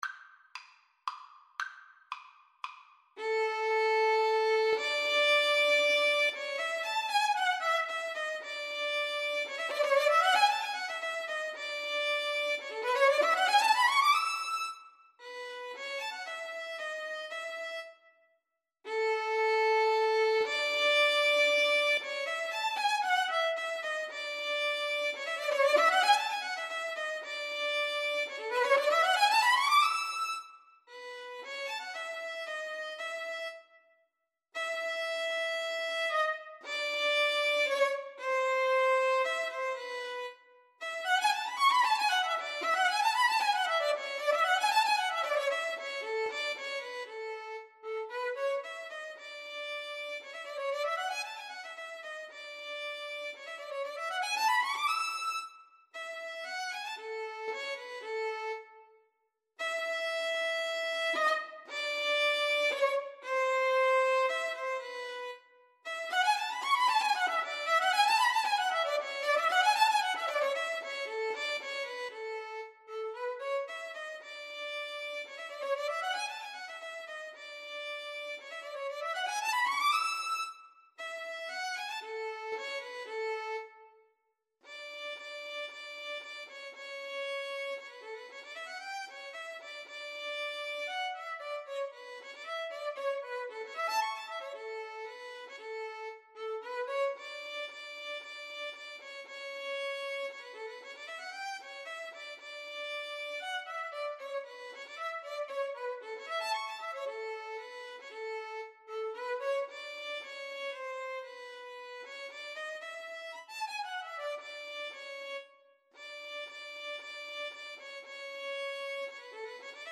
3/4 (View more 3/4 Music)
Classical (View more Classical Violin-Viola Duet Music)